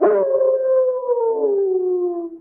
bdog_howl_1.ogg